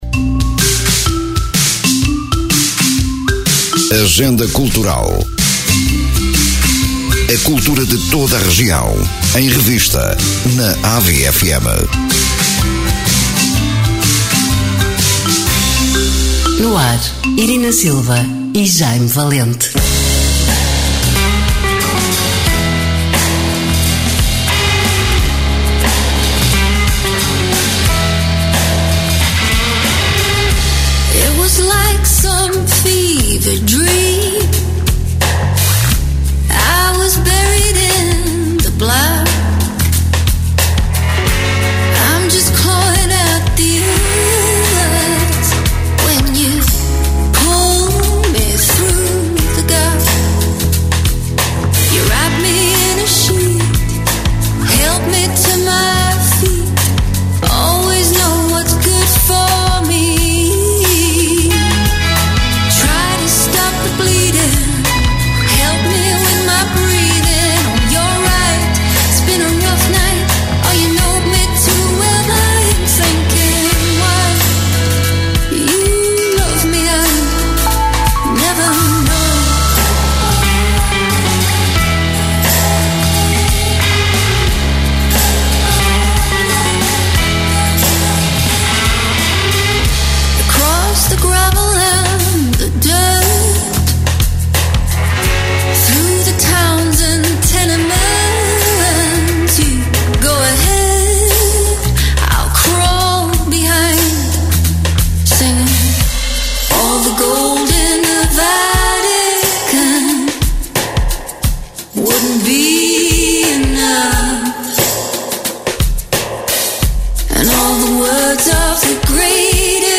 Emissão: 23 de Janeiro 2024 Descrição: Programa que apresenta uma visão da agenda cultural de Ovar e dos Concelhos vizinhos: Estarreja, Feira, Espinho, Oliveira de Azeméis, São João da Madeira, Albergaria-a-Velha, Aveiro e Ílhavo. Programa com conteúdos preparados para ilustrar os eventos a divulgar, com bandas sonoras devidamente enquadradas.